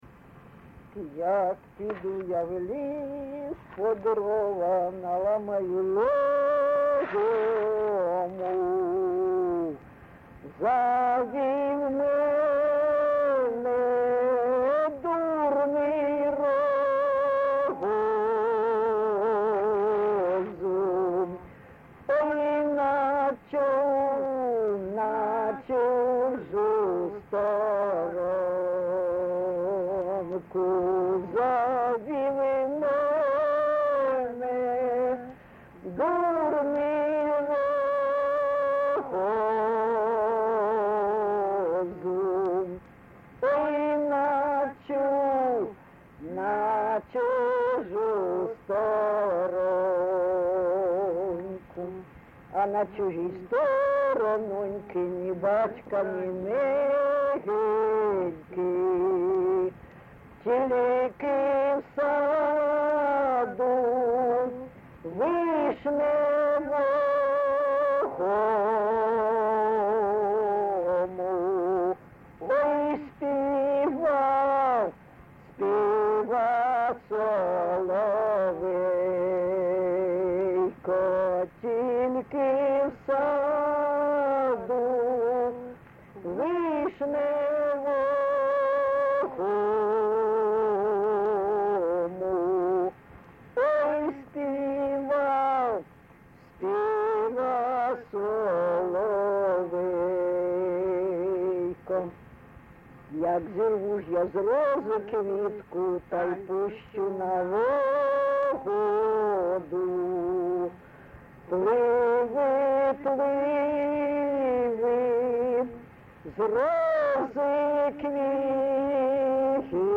ЖанрПісні з особистого та родинного життя
Місце записум. Дебальцеве, Горлівський район, Донецька обл., Україна, Слобожанщина